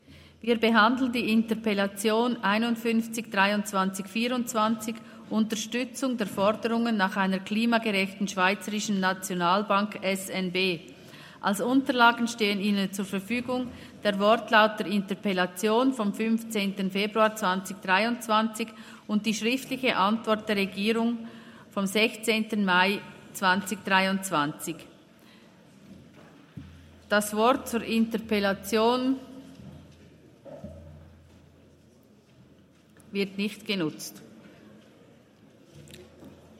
28.11.2023Wortmeldung
Session des Kantonsrates vom 27. bis 29. November 2023, Wintersession